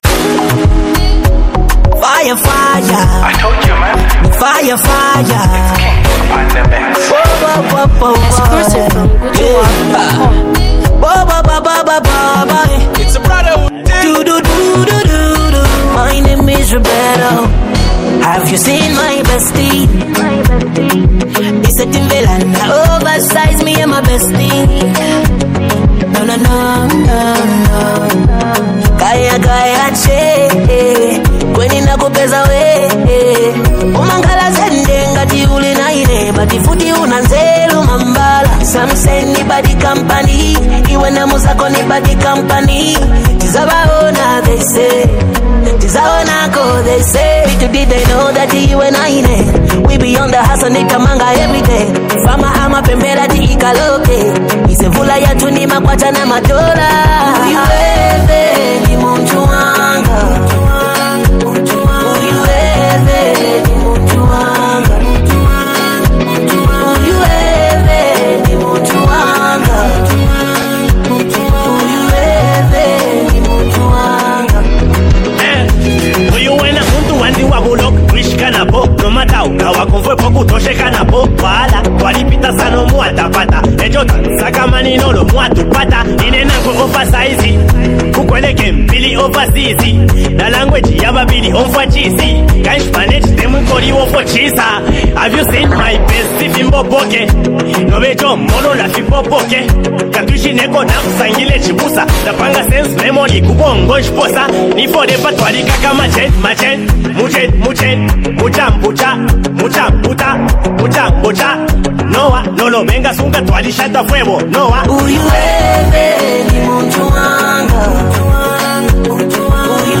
High-Energy Vibes